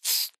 Sound / Minecraft / mob / silverfish / say1.ogg